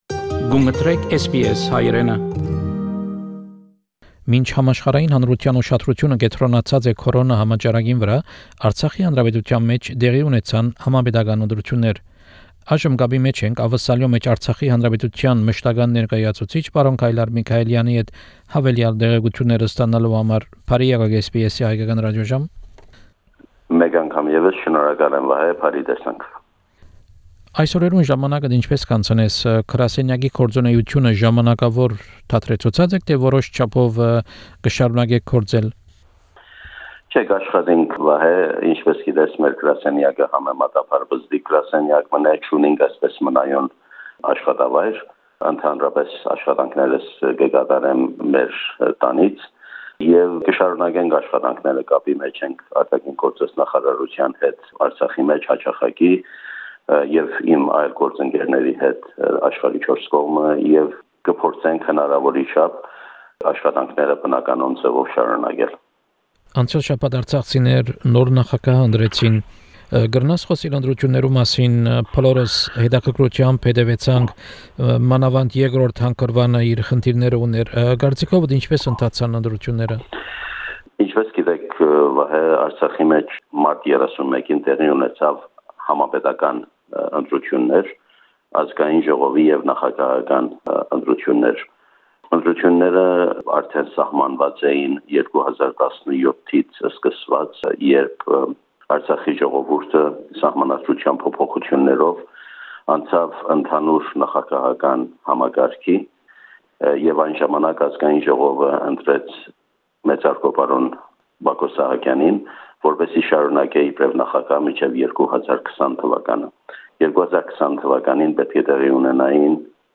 Interview (in Armenian) with Mr. Kaylar Michaelian the permanent representative of Artsakh Republic in Australia. The main topic of the interview is the recent general elections in Artsakh.